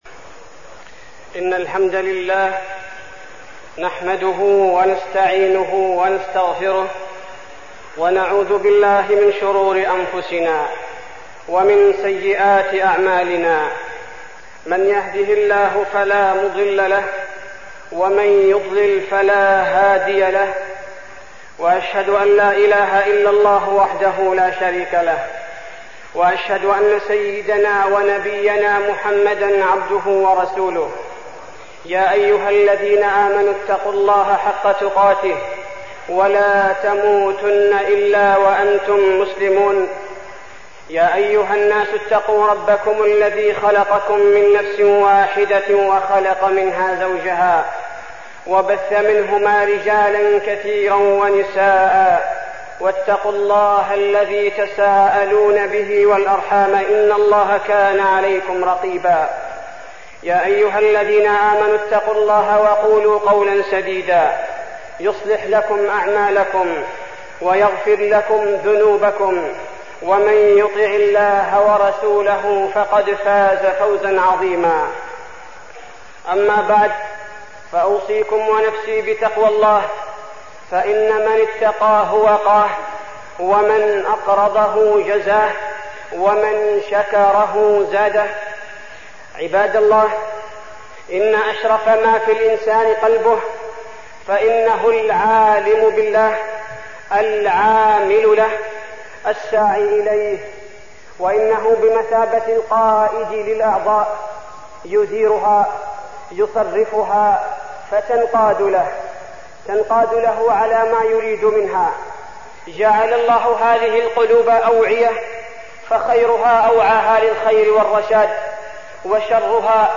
تاريخ النشر ١١ رجب ١٤١٧ هـ المكان: المسجد النبوي الشيخ: فضيلة الشيخ عبدالباري الثبيتي فضيلة الشيخ عبدالباري الثبيتي أمراض القلوب